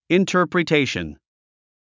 発音 intə̀ːrprətéiʃən インタァプリテイション